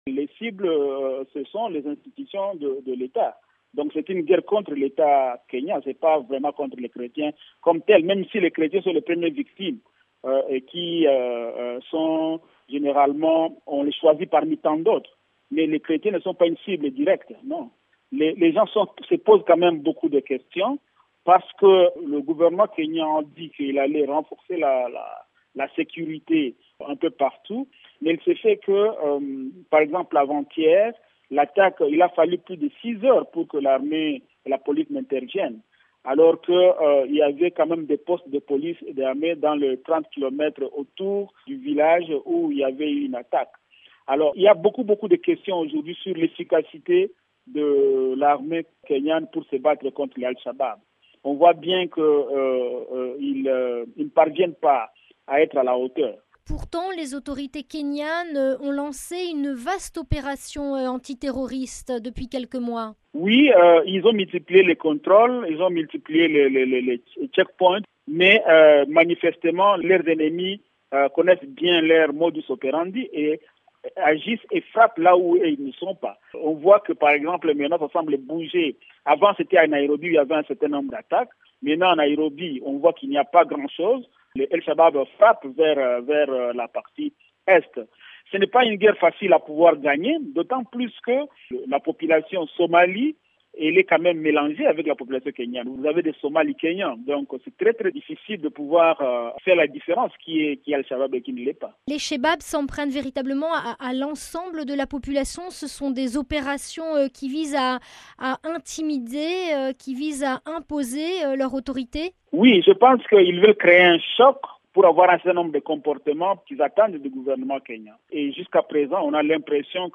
(RV) Entretiens - En guerre contre le pouvoir kényan, les islamistes somaliens shebab ont poursuivi leurs attaques meurtrières le long de la côte touristique de l'océan Indien, avec un nouveau raid qui a fait au moins neuf morts lundi soir près de l'archipel de Lamu.